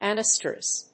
anoestrus.mp3